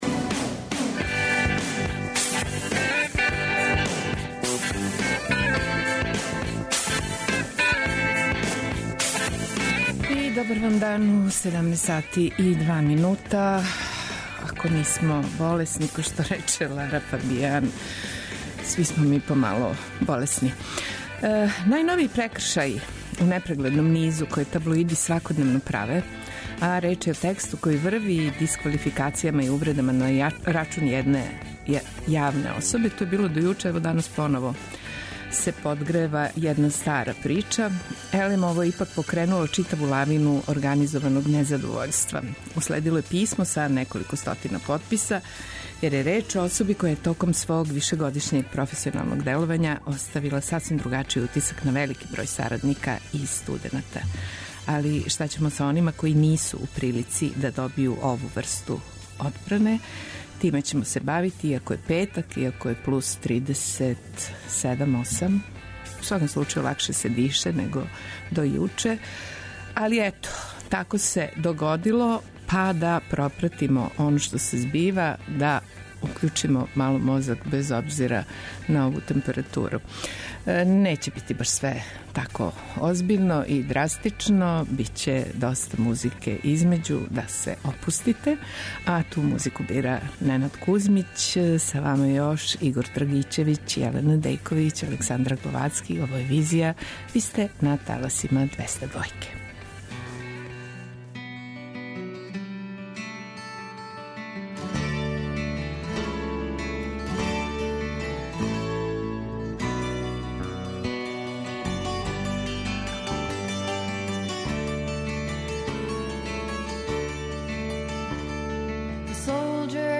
Куда то иде новинарство? Шта Интернет доноси добро, а шта лоше у овом контексту – питања су на које у данашњој Визији своје одговоре нуде уметници и новинари.